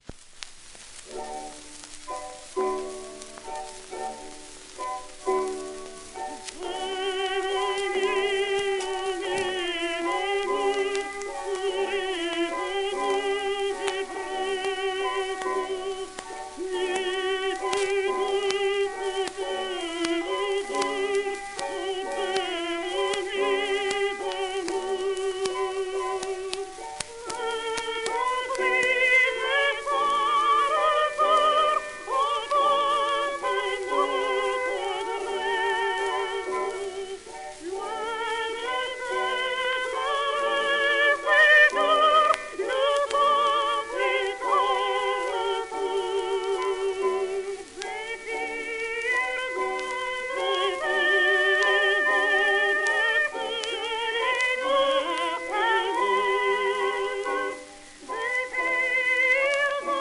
オーケストラ
盤質A- *小キズ[クリック音あり]、薄い面擦れ
旧 旧吹込みの略、電気録音以前の機械式録音盤（ラッパ吹込み）